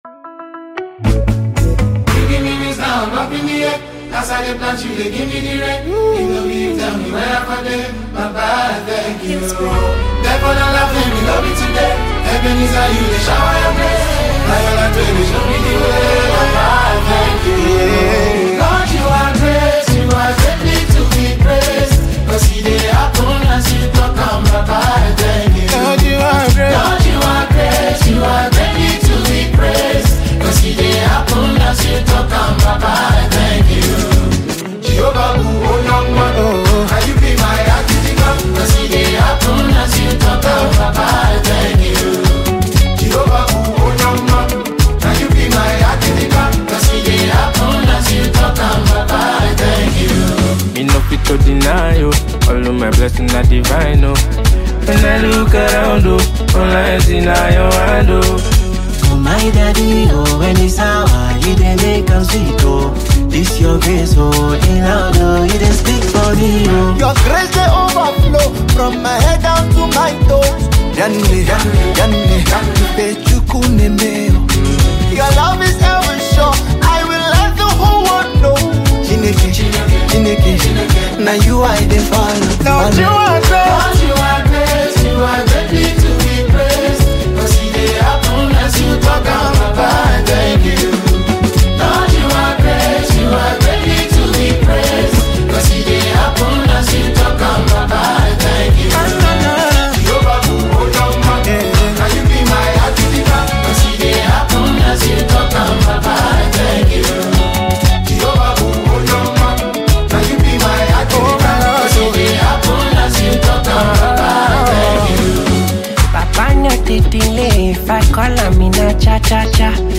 the esteemed Nigerian gospel artist